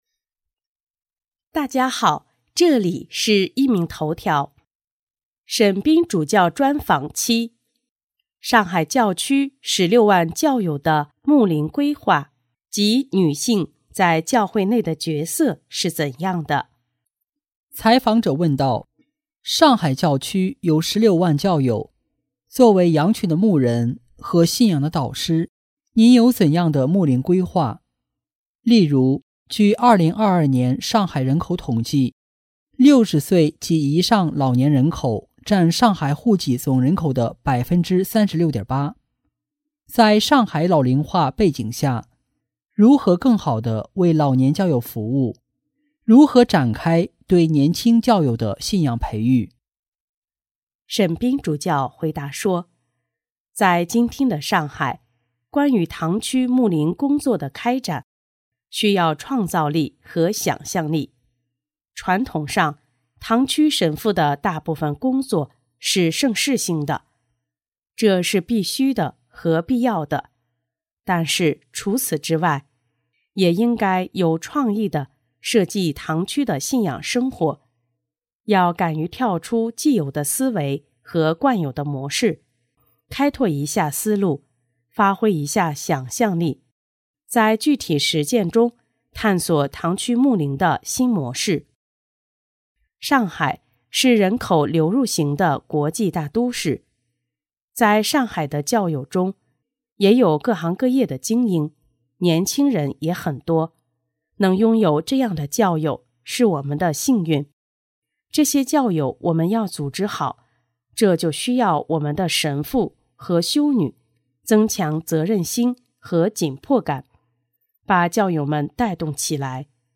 【壹明头条】|沈斌主教专访(七)：关于上海教区16万教友的牧灵规划及信仰培育……